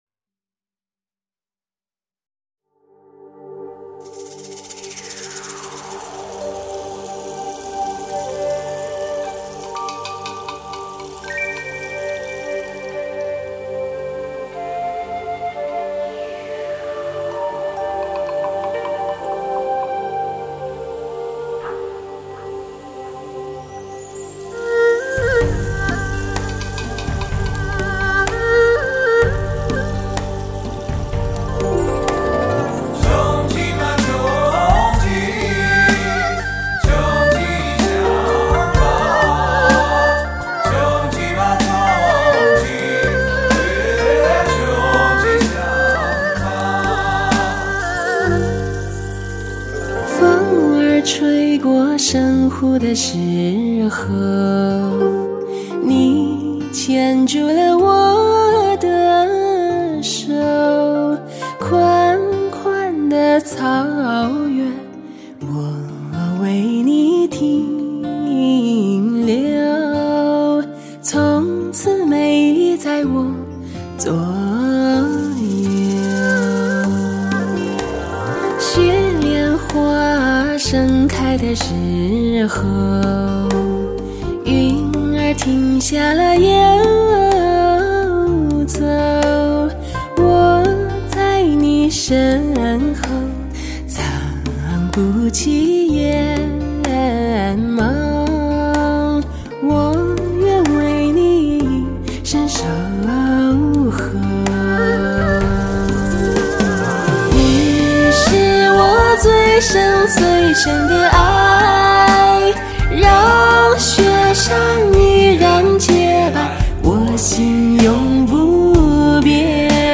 最佳HIFI音效，最佳HIFI人声音色。